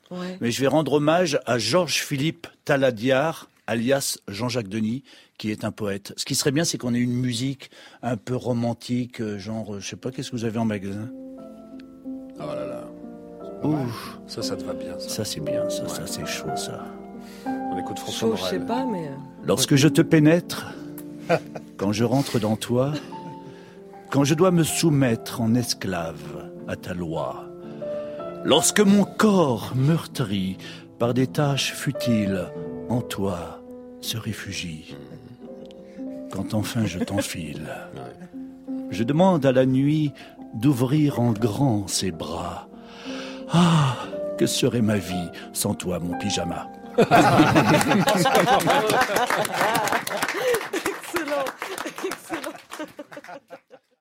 Un spectacle cabaret où les chansons sont entrecoupées de petits plats.
accordéon et chant
batterie et chant
guitare, basse et chant